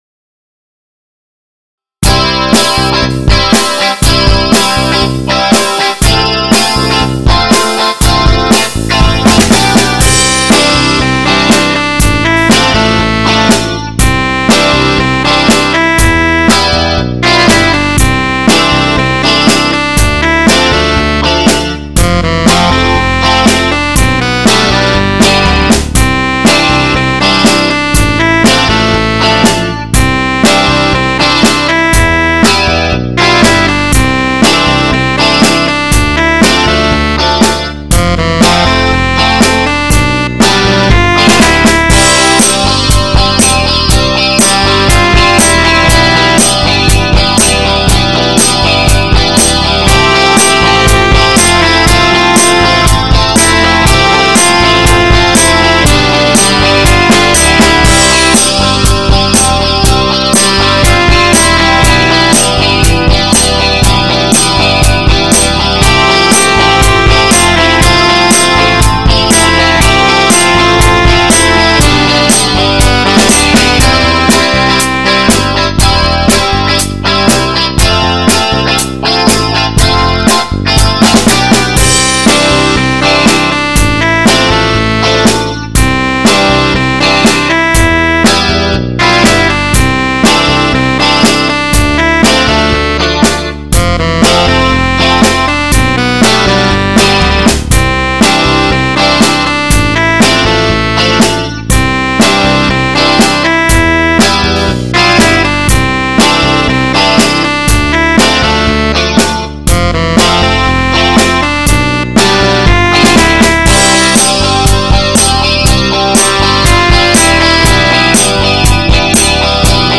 打ち込みで作った創作メインの音楽をアップしてます。
Ａメロに昔作ったＢメロを合体、
スローにしたかったけどあんまりスローじゃない曲。